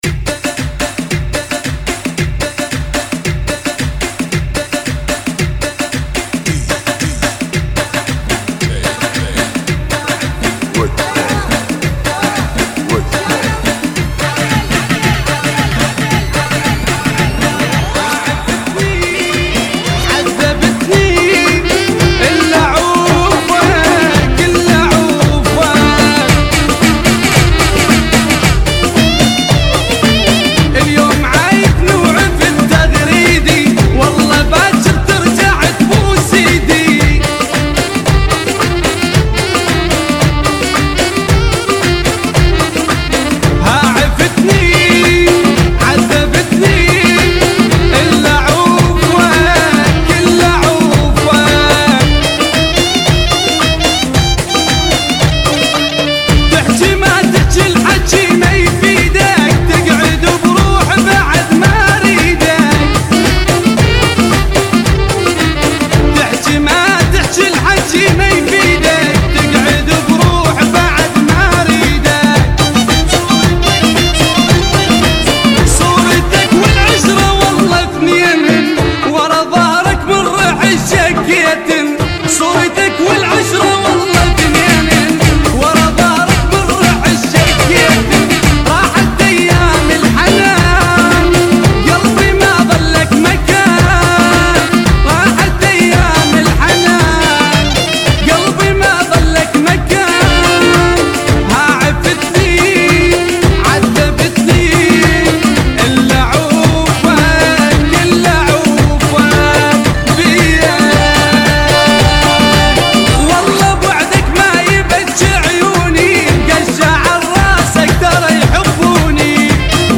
112 BPM